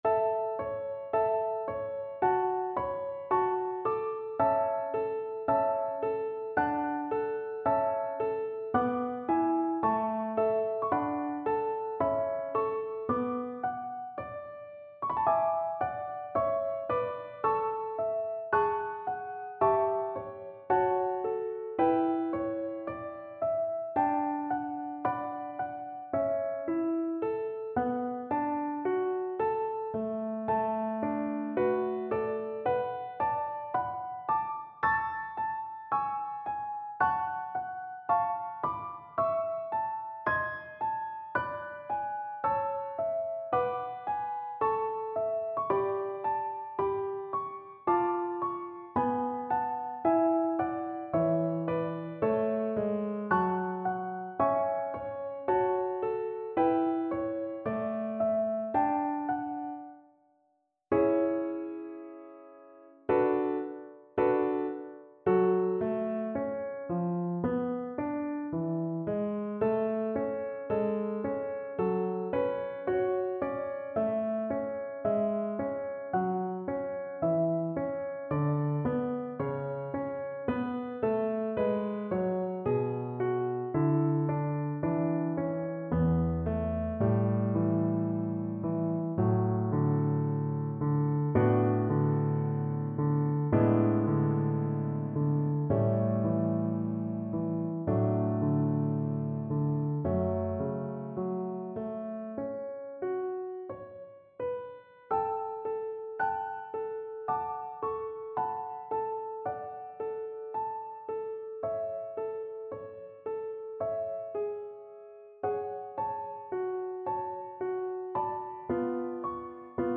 Violin
Traditional Music of unknown author.
A major (Sounding Pitch) (View more A major Music for Violin )
4/4 (View more 4/4 Music)
Andante
Classical (View more Classical Violin Music)